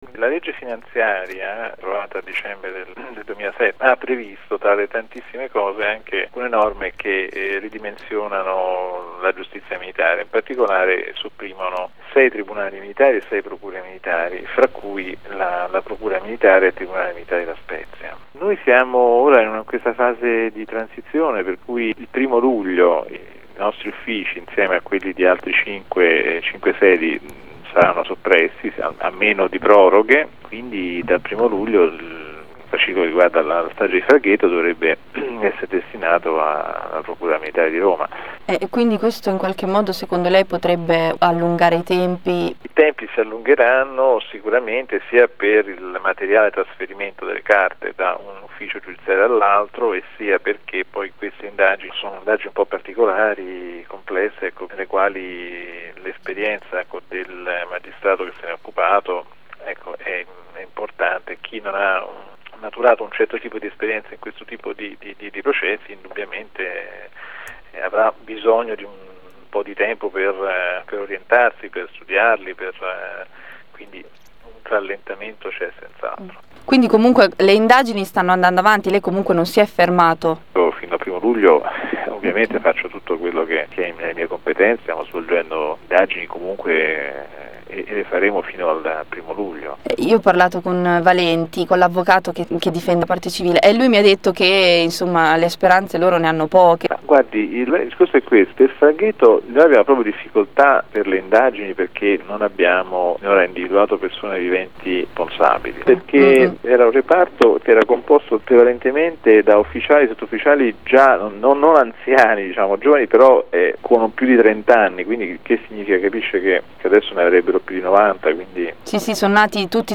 Ascolta l'intervista al magistrato Marco De Paolis, procuratore del tribunale militare di La Spezia, titolare dell'inchiesta (clicca qui per ascoltare il file)